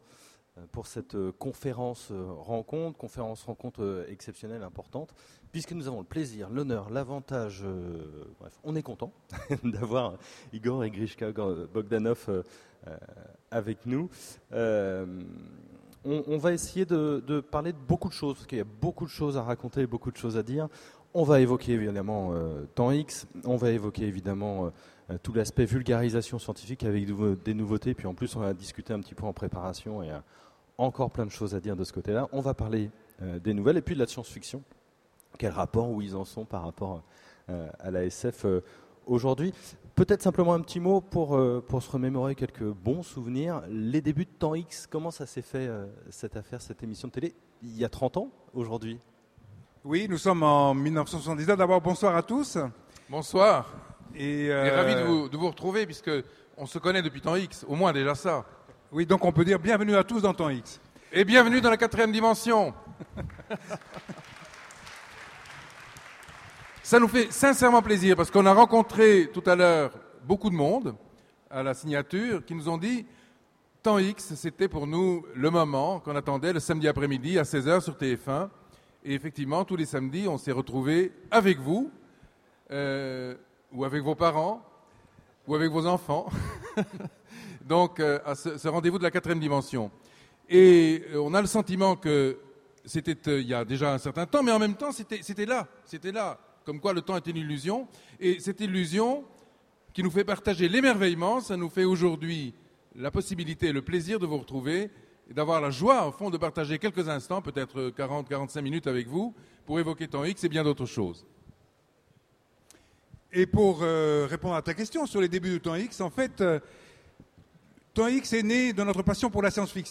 On vous repasse la conférences des Utopiales qu'ils avaient animée en 2009.